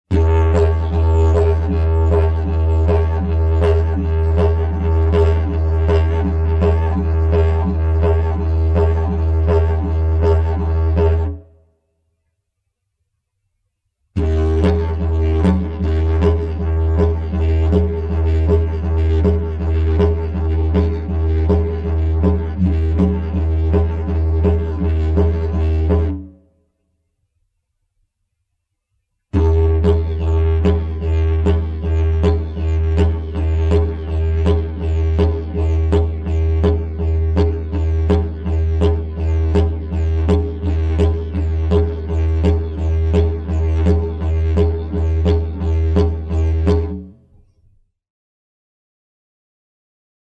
LA RESPIRAZIONE E IL DIDGERIDOO
Sample n°3 contiene: respirazione circolare di guance, mandibola e lingua.